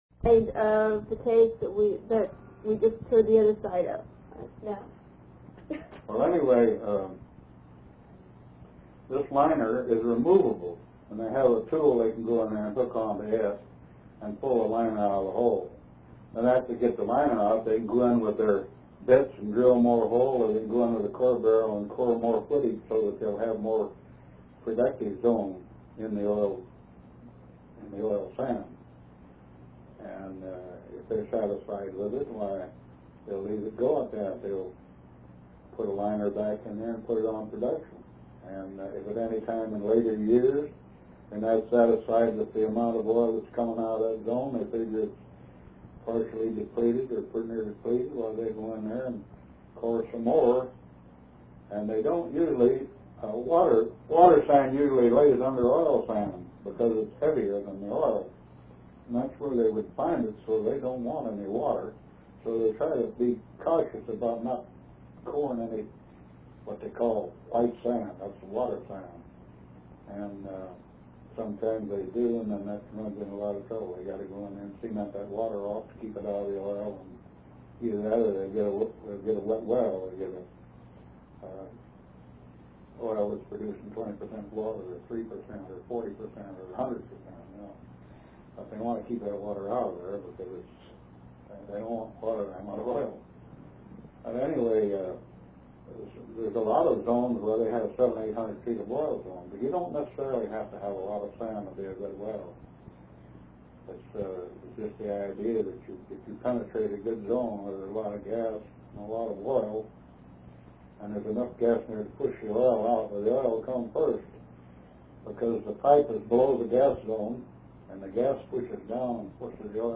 INTERVIEW DESCRIPTION
Although he was retired, he still spoke in a strong voice and described his work in colorful language.